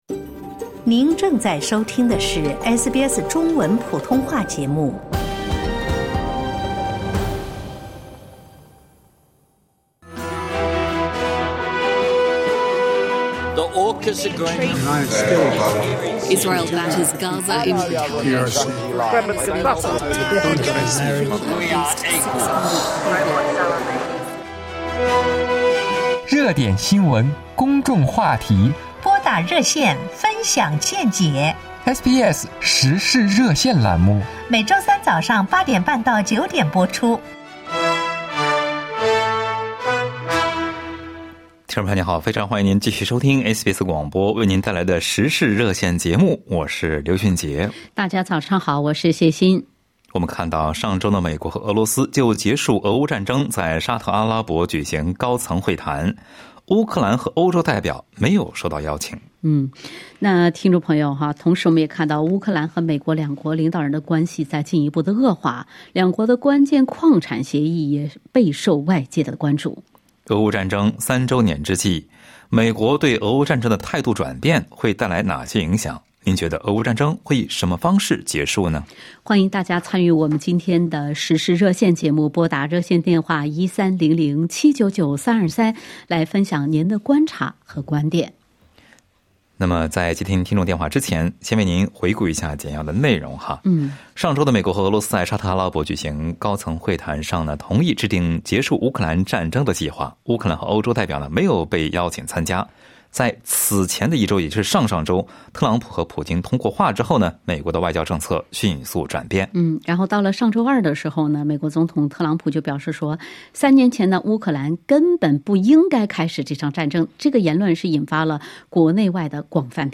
在本期《时事热线》节目中，听友们就此表达了各自的看法。